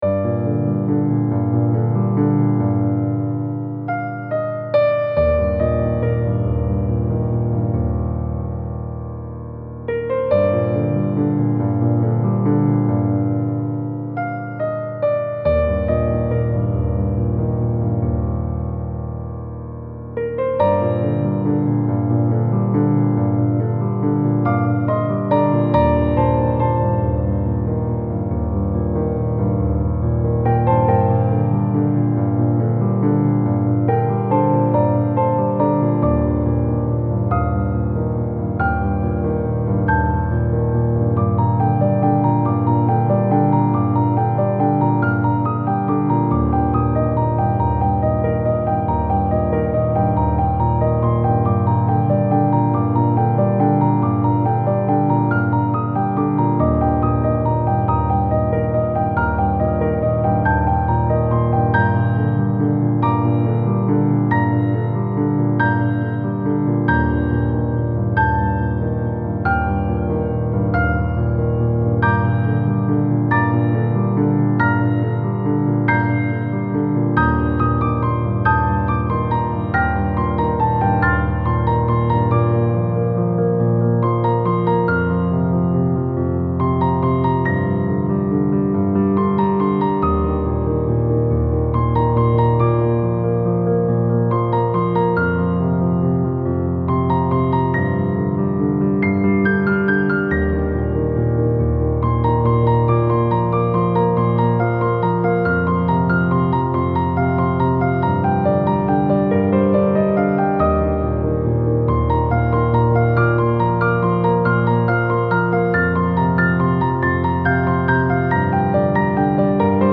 Style Style Soundtrack
Mood Mood Uplifting
Featured Featured Piano
BPM BPM 140